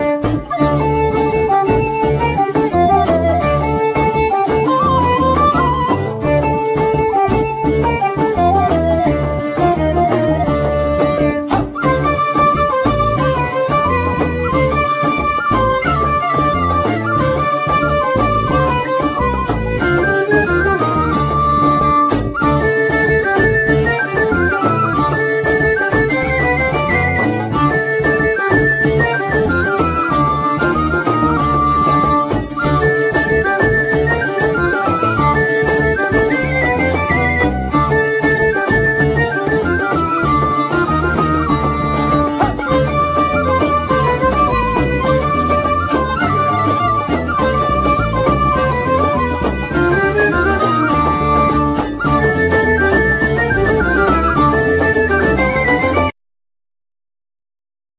Flute,Percussions,Gittern,Vocals
Gittern,Saz,Tarabuka,Vocals
Five strings fiddle,Percussions,Vocal
Soprano fiddle,Tarabuka,Vocal
Davul,Tamburello,Trabuka,Vocal
Double bass